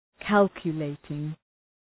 Προφορά
{‘kælkjə,leıtıŋ} (Επίθετο) ● υπολογιστικός (Ουσιαστικό) ● υπολογιστής